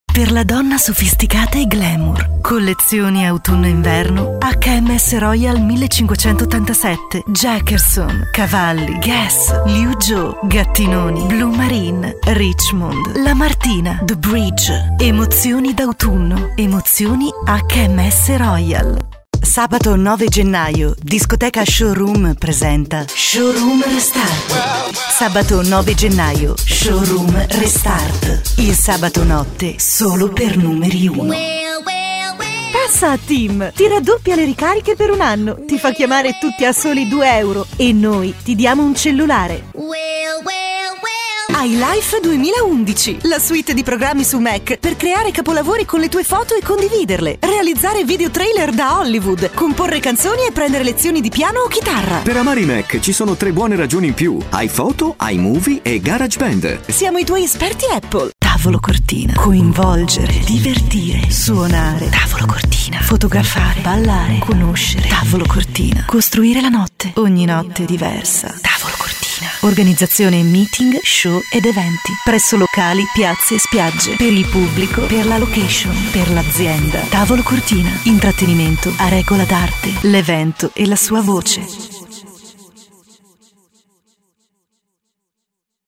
Voce versatile per voiceovers, radio show, pubblicità e messaggi di segreteria telefonica
Sprechprobe: Werbung (Muttersprache):
Italian Voice Over Talent & Radio Personality for narrations, advertising, documentaries and phone messages